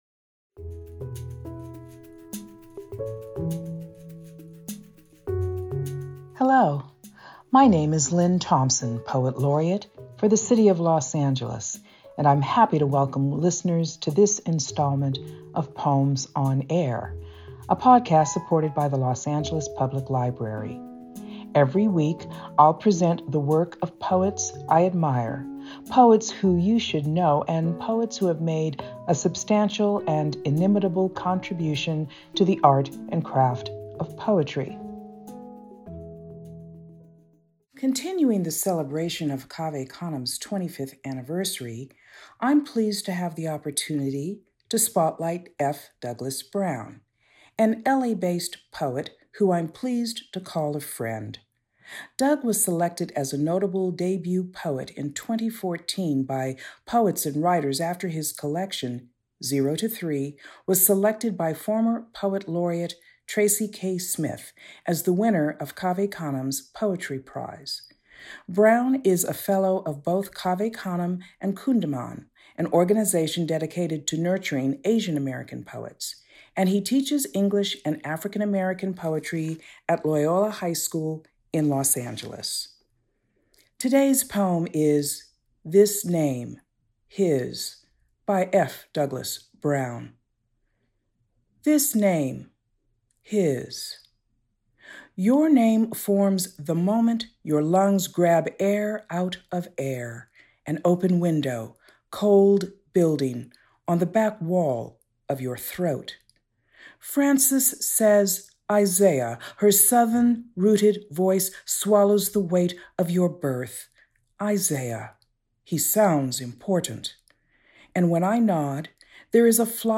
Teens and adults are able to listen to guests discuss their chosen career and the path it took to get there. These relaxed conversations are a great insight into your dream career!